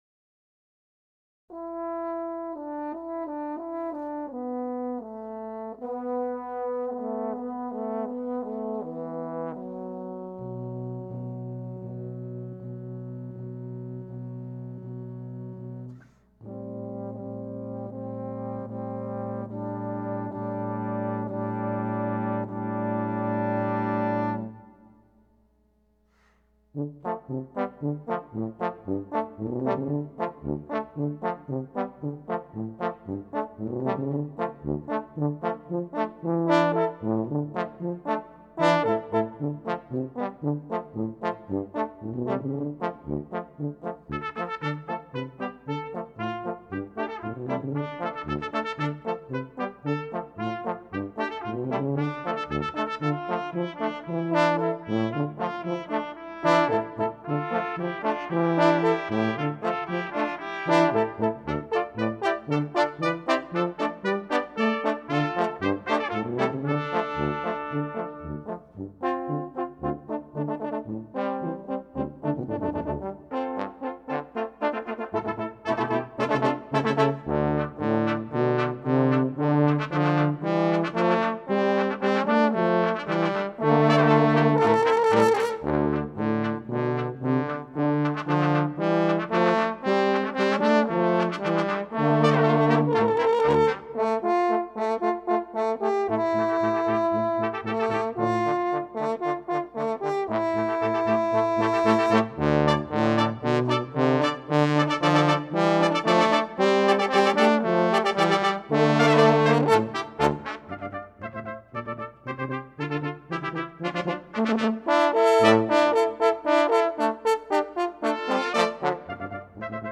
for Brass Quintet (2007)